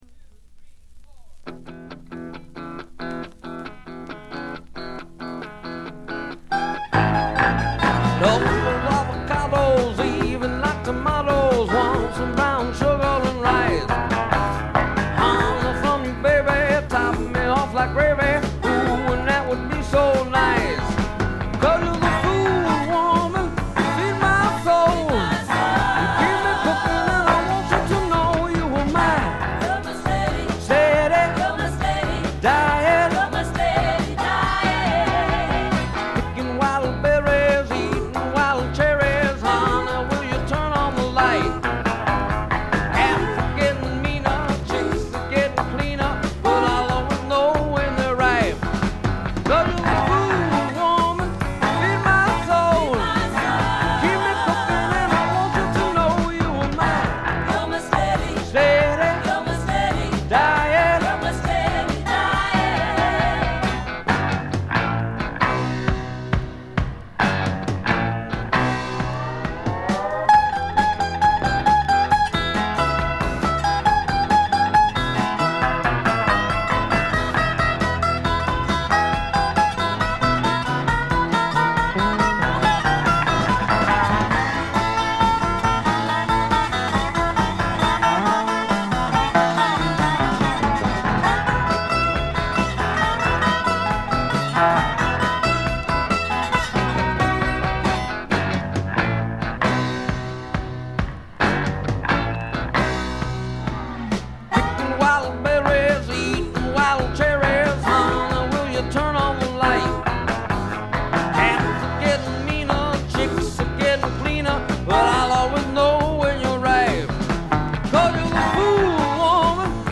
軽微なバックグラウンドノイズ、チリプチ程度。
知られざるスワンプ系シンガー・ソングライターの裏名盤です。
試聴曲は現品からの取り込み音源です。